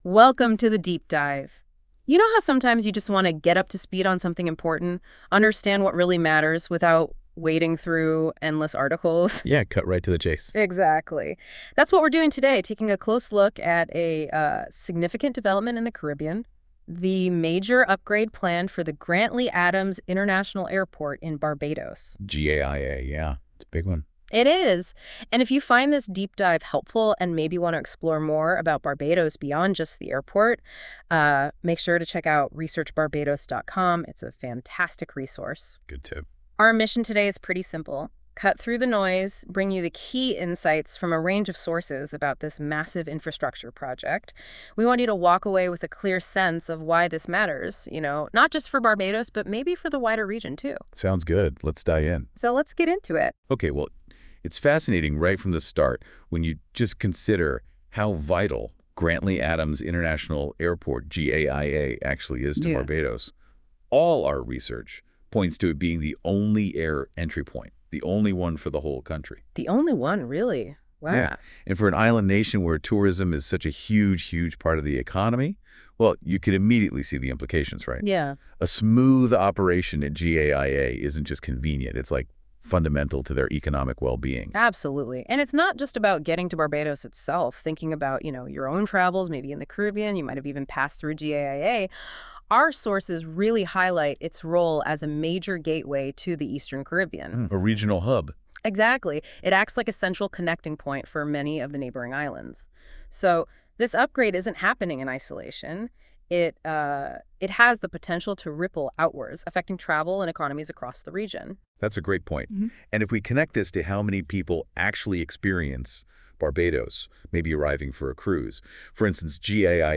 Exclusive expert commentary on Barbados' airport transformation